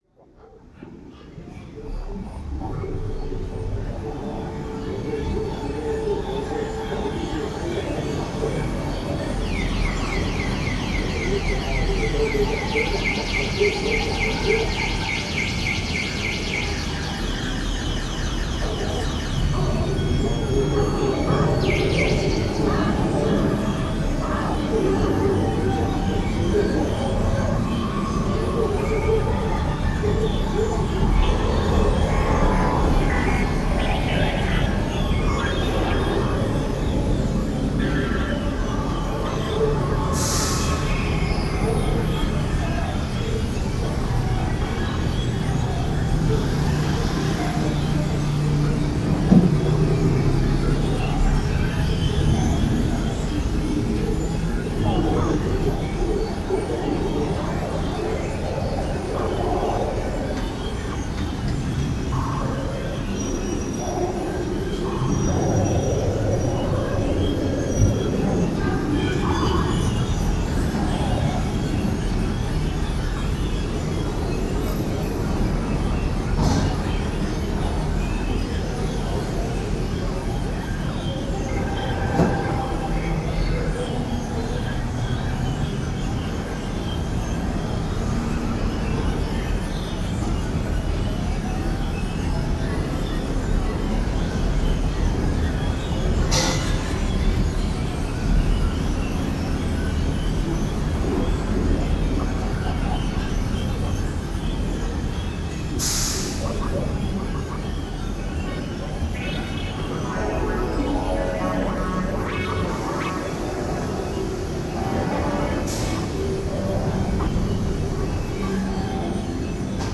Genre: experimental, electronic.